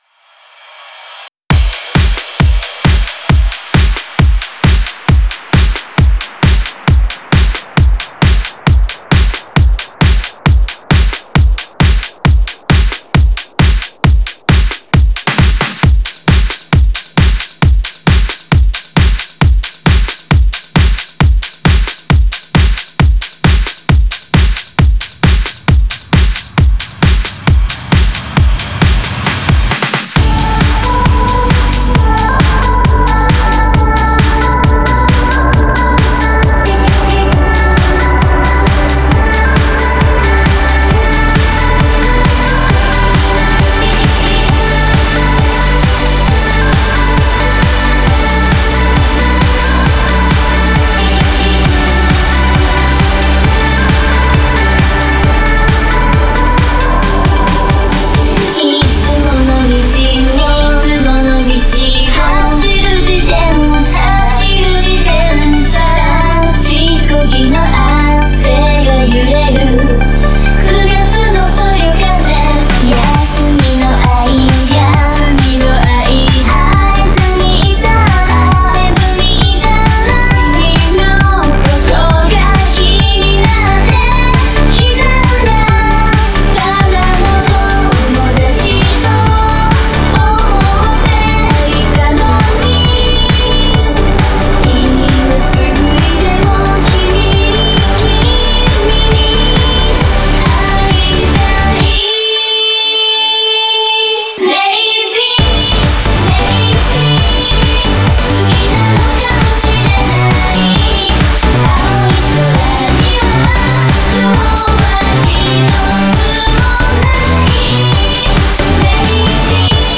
Genre(s): House/Trance